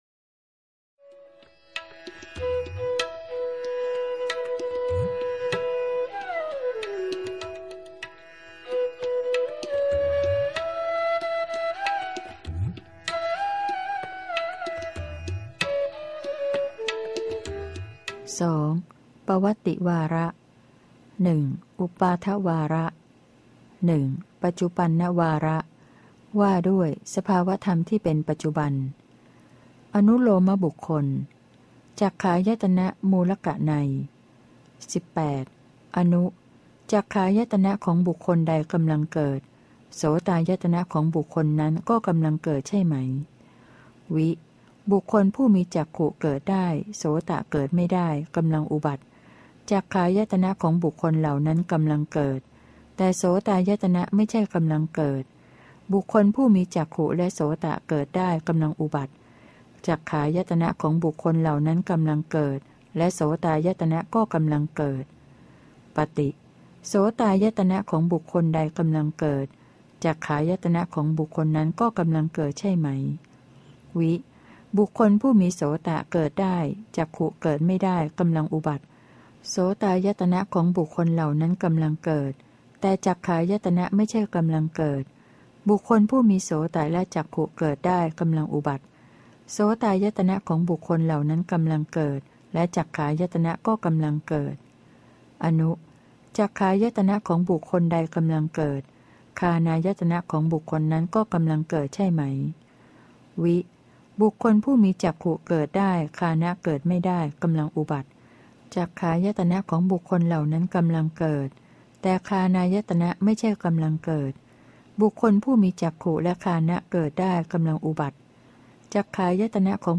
พระไตรปิฎก ภาคเสียงอ่าน ฉบับมหาจุฬาลงกรณราชวิทยาลัย - เล่มที่ ๓๘ พระอภิธรรมปิฏก